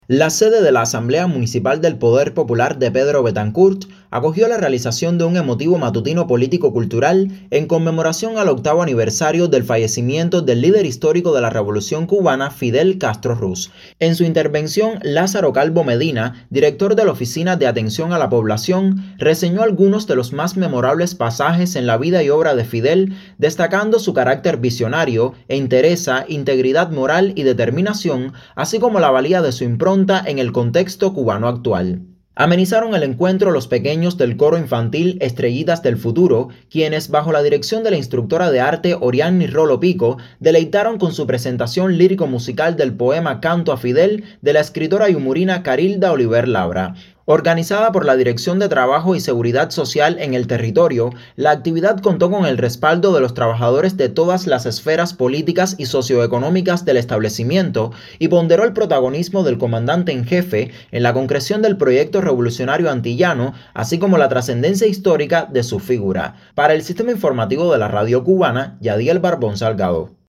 PEDRO BETANCOURT.-La sede de la Asamblea municipal del Poder Popular en esta localidad acogió la realización de un emotivo matutino político - cultural en conmemoración al octavo aniversario del fallecimiento del líder histórico de la Revolución cubana, Fidel Castro Ruz.